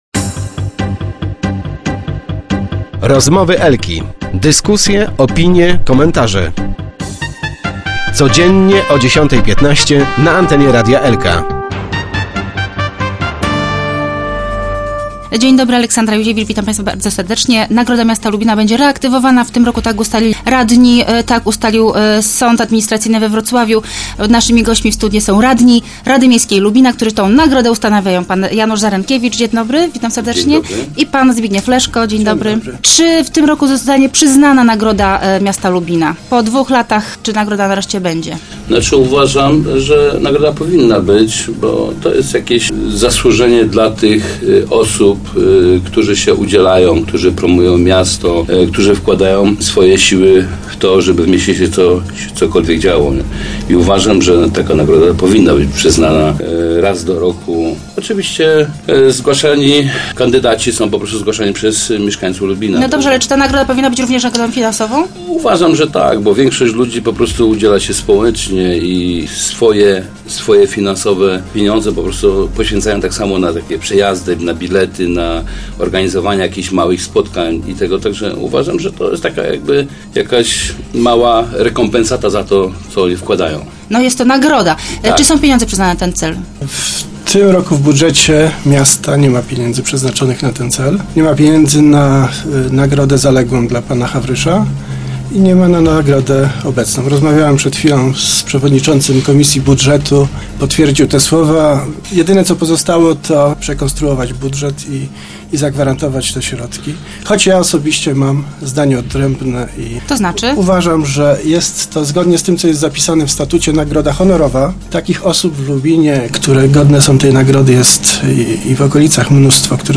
Tymczasem toczą się rozmowy wśród radnych czy nagroda powinna mieć wymiar finansowy, czy pamiątkowego dyplomu. Przed mikrofonem w lubińskim studiu Radia Elka dyskutowali na ten temat radni Janusz Zarenkiewicz i Zbigniew Leszko.